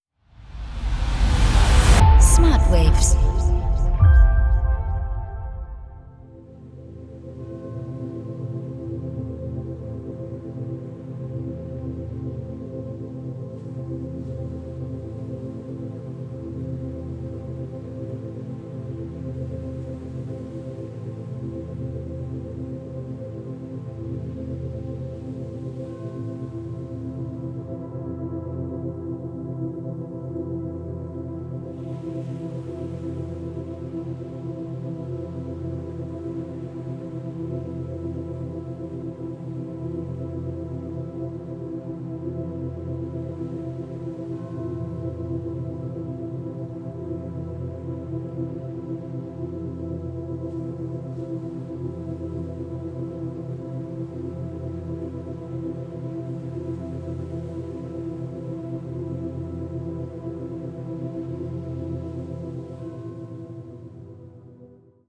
0,5-4 Hertz Delta Wellen Frequenzen